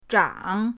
zhang3.mp3